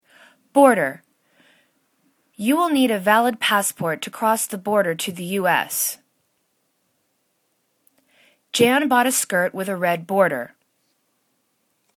bor.der    /baw:rdәr/     [C]